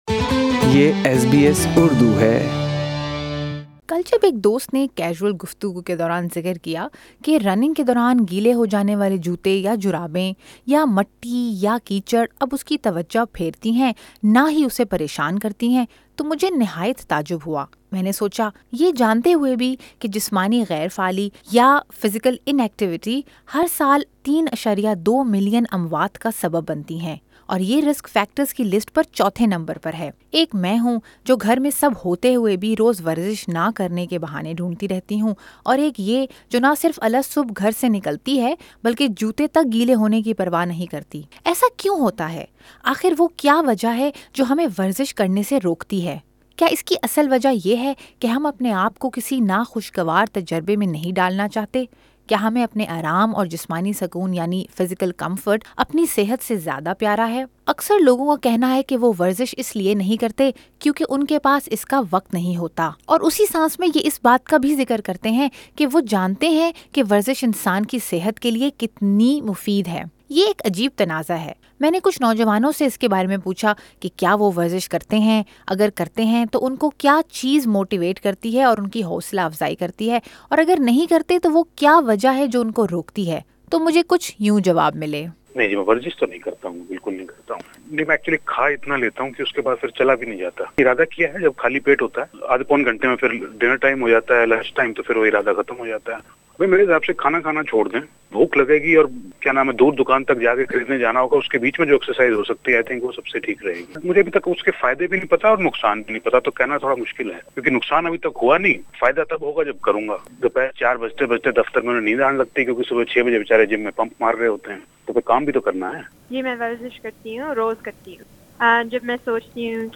لوگوں کو ورزش کرنے سے کیا بات روکتی ہے؟ میں نے کچھ نوجوانوں سے اس بارے میں بات کی اورایک فٹنس کوچ سے وجہ جاننے کی کوشش بھی-